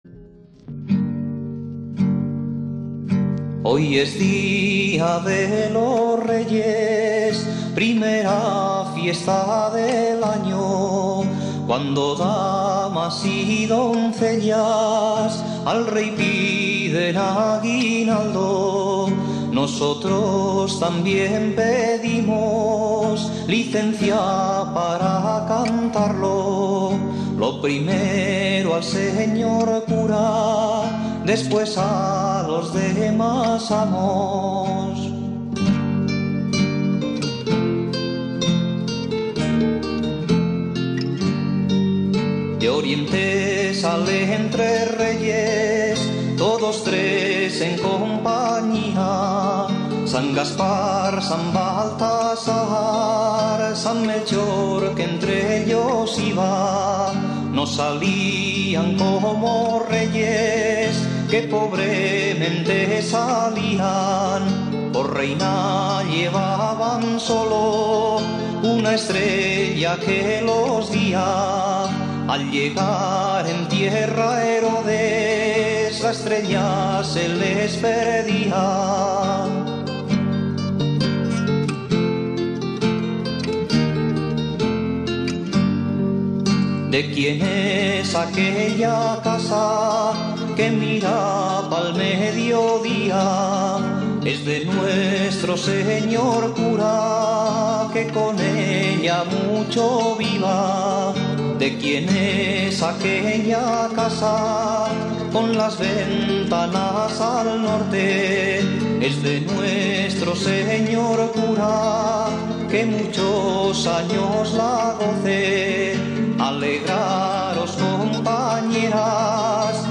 Guitarra y voz
Bajo